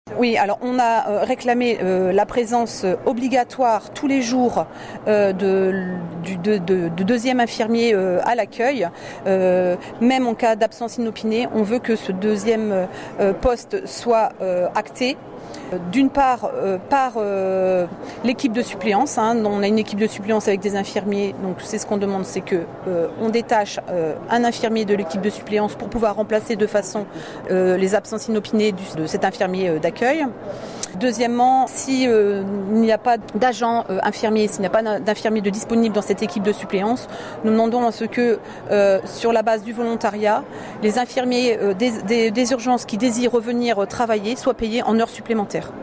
Alors que la direction du centre hospitalier organisait hier midi (jeudi) sa cérémonie des vœux, une délégation de la CGT s’est fait entendre.